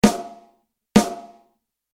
Drumset-Mikrofonierung: Snare-Alternativen
Zusätzliche Mikrofonierung der Snare-Unterseite
Der Hauptgrund für die Abnahme der Unterseite der Snardrum ist die feinere Abbildung des Snare-Teppichs im Gesamtklang.
Da der Snare-Teppich einen hellen, hohen Klang produziert, dessen Frequenzspektrum dem der Cymbals nicht unähnlich ist, empfiehlt es sich durchaus, ein Kondensator-Mikrofon einzusetzen.